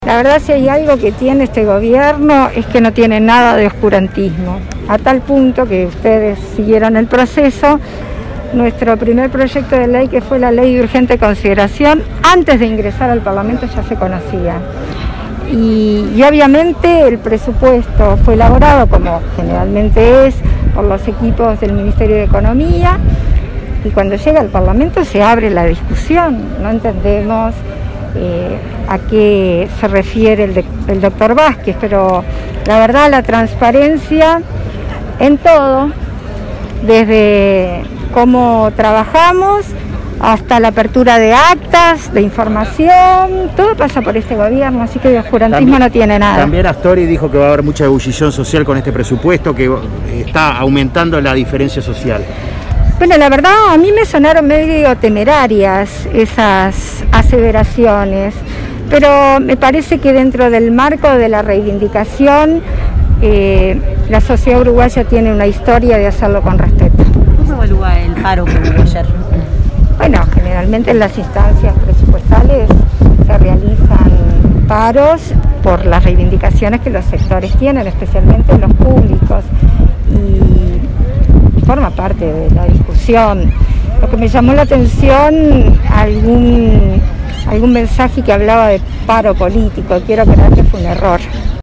Escuche las declaraciones completas de la vicepresidenta Beatriz Argimón.